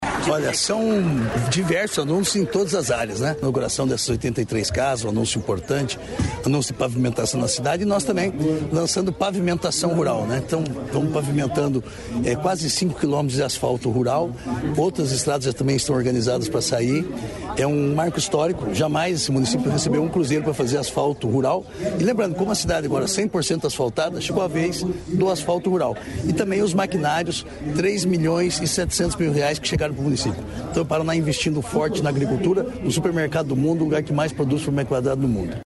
Sonora do secretário da Agricultura e abastecimento, Márcio Nunes, sobre os investimentos em Siqueira Campos